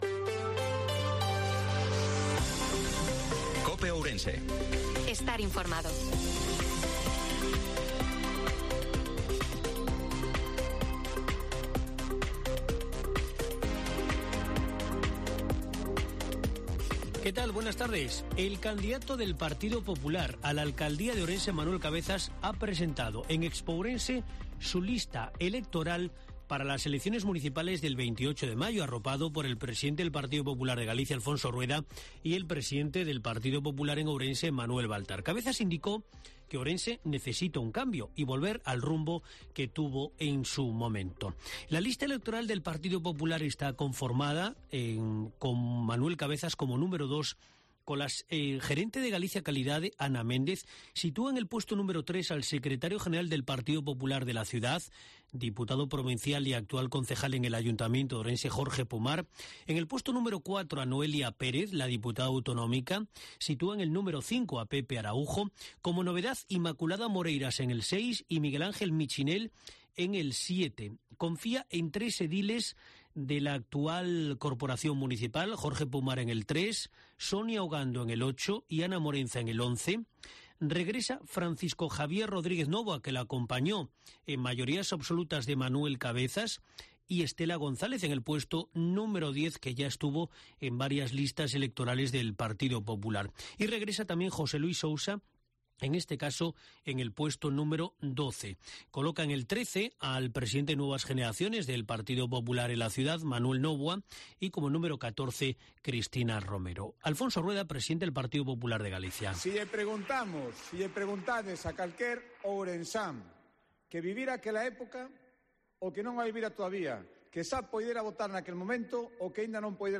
INFORMATIVO MEDIODIA COPE OURENSE-20/04/2024